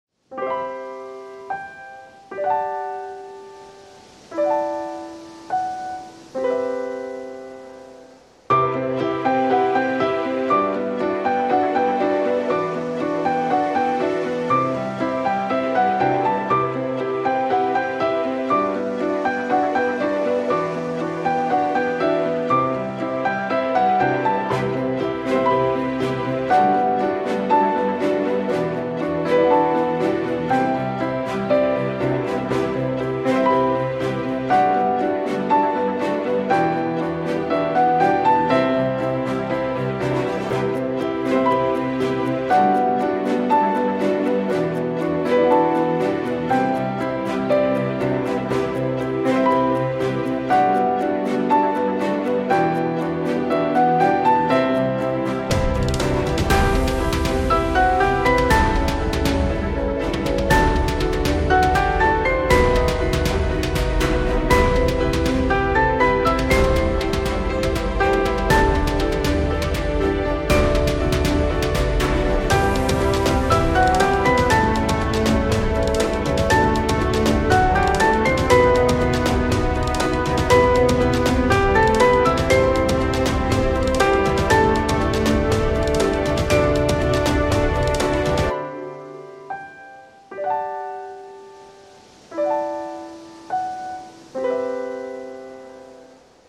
Как раз достаточно близкий общий образ (~10м от оркестра).